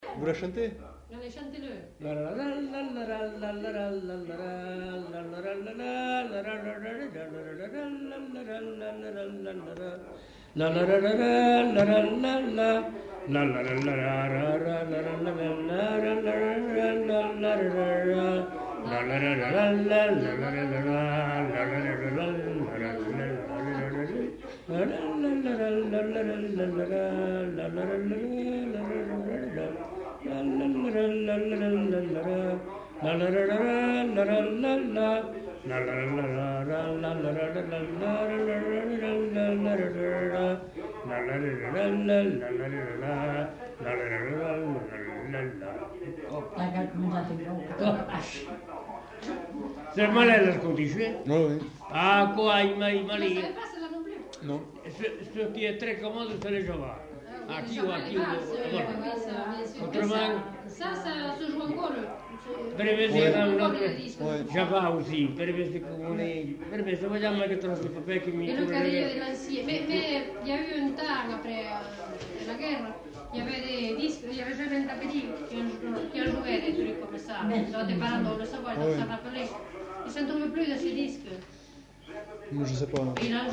Lieu : Pavie
Genre : chant
Effectif : 1
Type de voix : voix d'homme
Production du son : fredonné
Danse : scottish